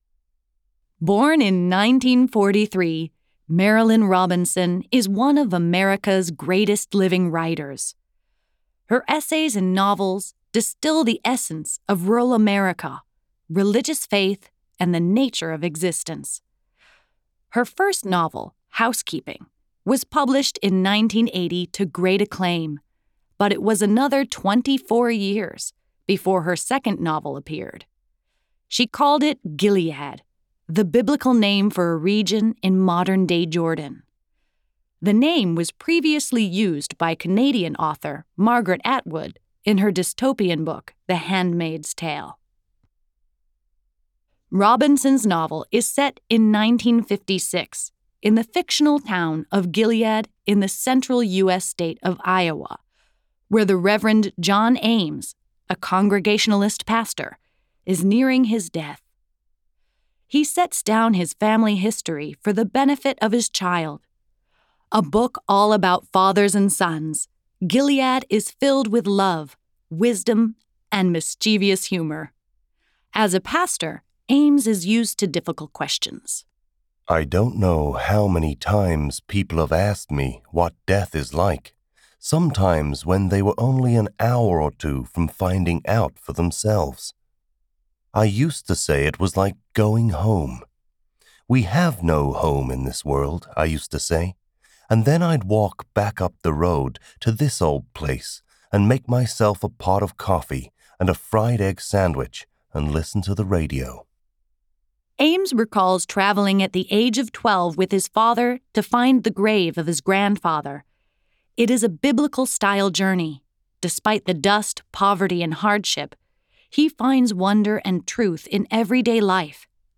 Speaker (UK accent)
Speaker (American accent)